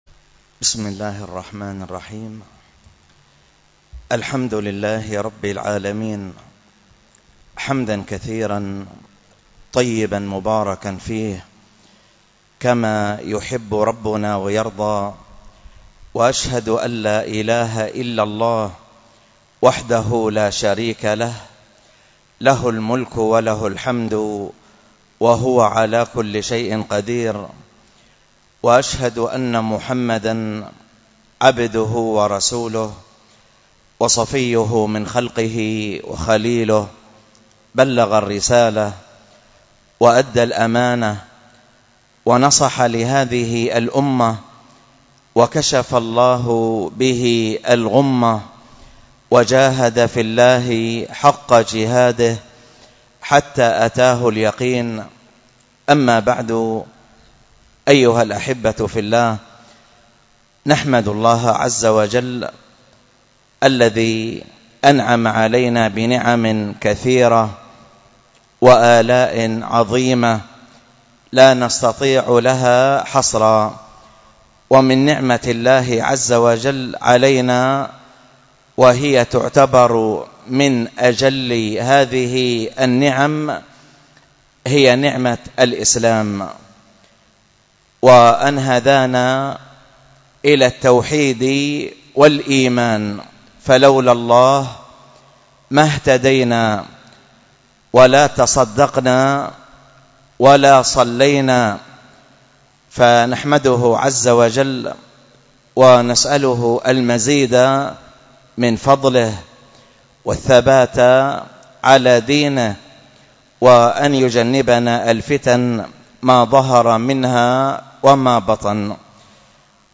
المحاضرة
رحمه الله تعالى -، والتي كانت بمسجد السنة بدار الحديث بطيبة بالشحر